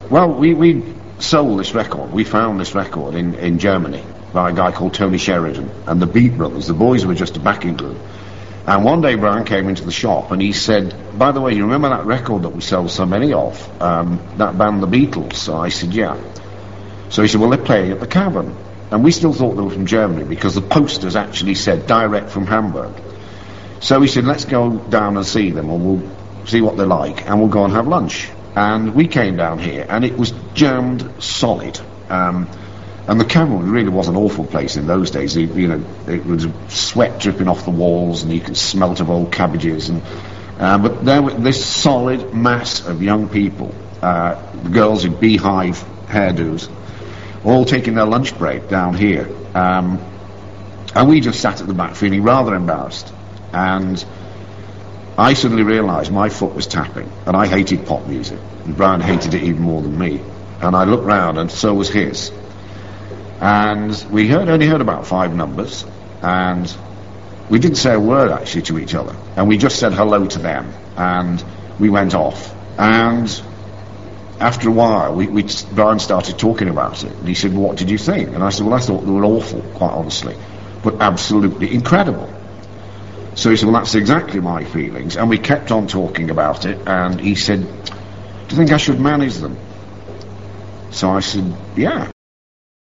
interview
at the re-created Cavern Club in 1995